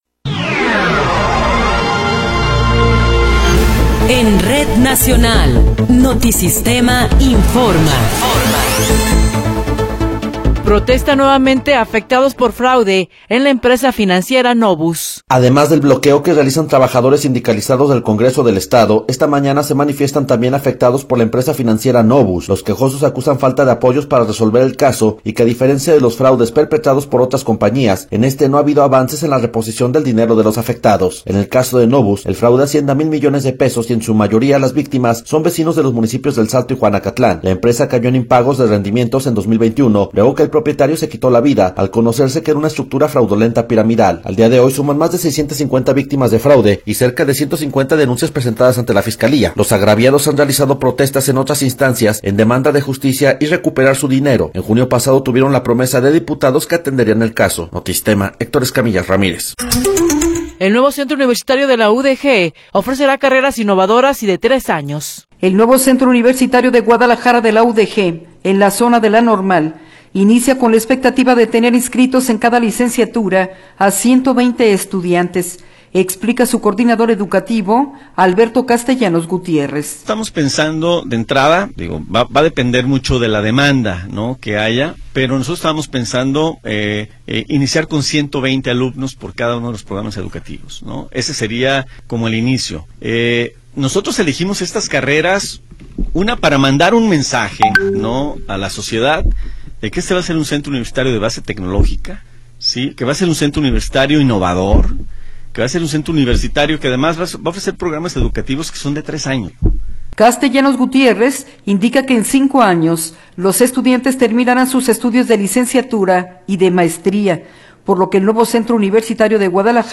Noticiero 13 hrs. – 7 de Marzo de 2024
Resumen informativo Notisistema, la mejor y más completa información cada hora en la hora.